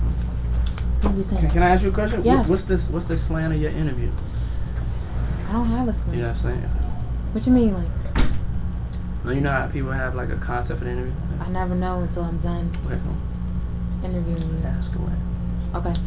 Oh! And a prize to the first reader who can tell me who is asking me the dreaded question in this clip…